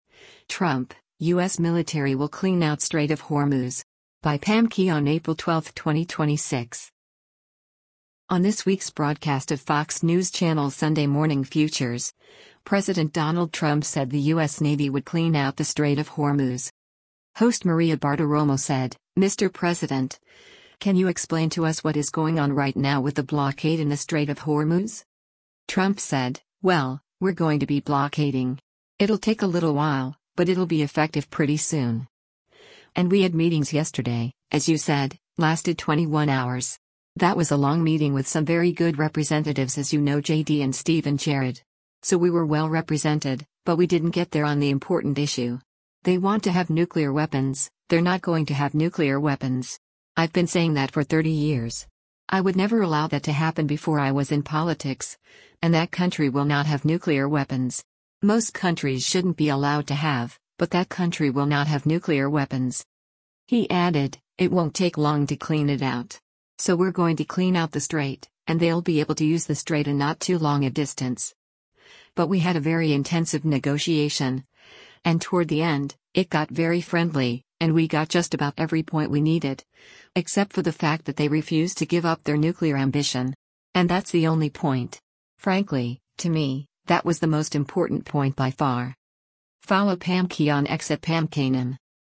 On this week’s broadcast of Fox News Channel’s “Sunday Morning Futures,” President Donald Trump said the U.S. Navy would “clean out” the Strait of Hormuz.